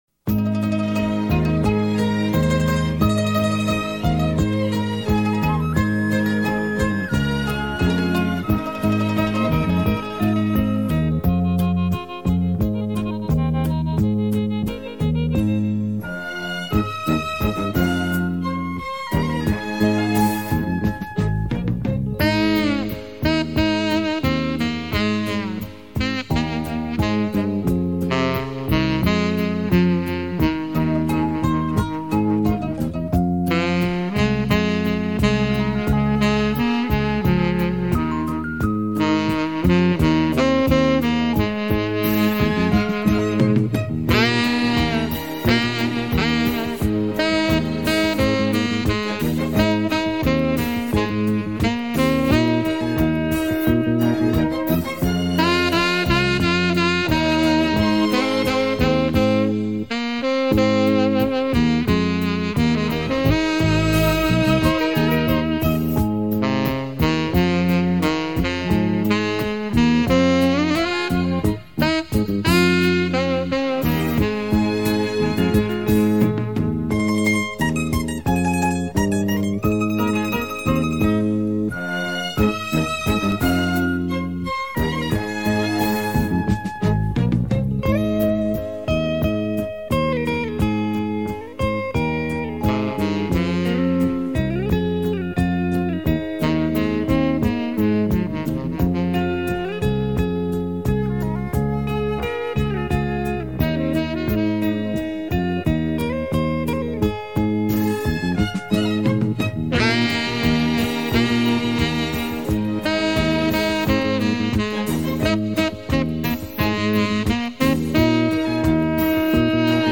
浪漫的旋律